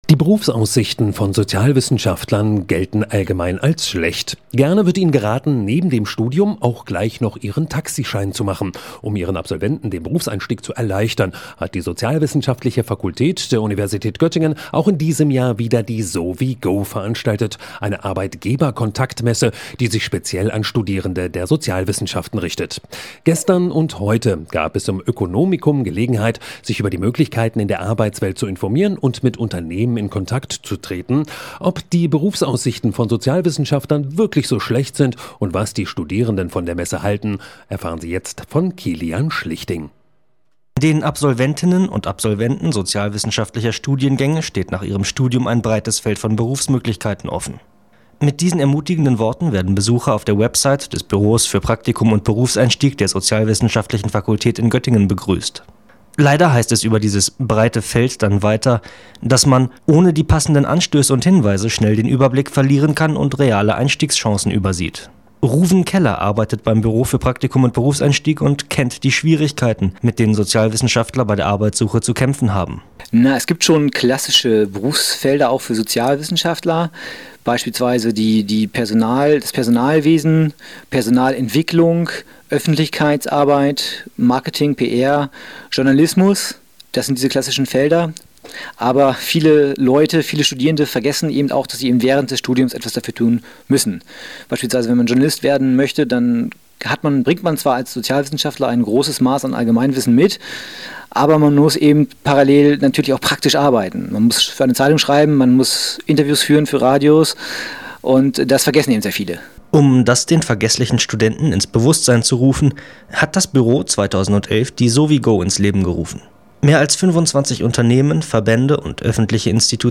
Interview vom Stadtradio vom 27.11.2012